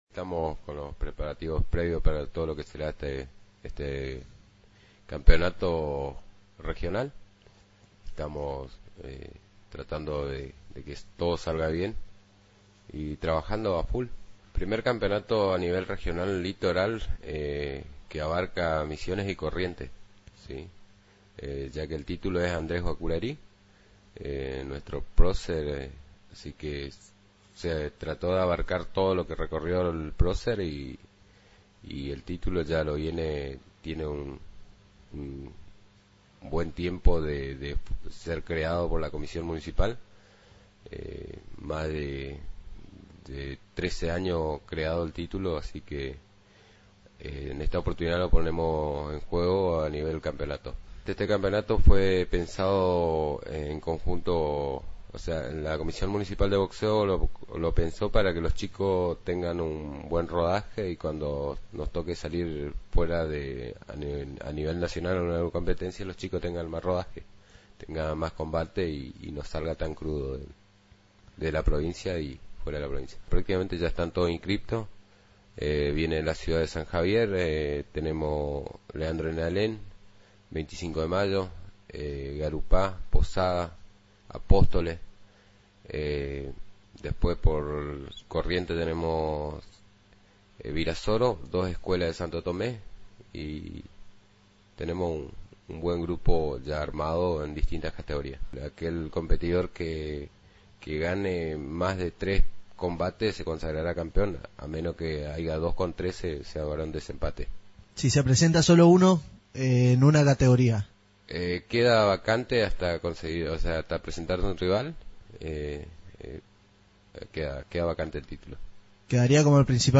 En visita al programa R.P.M.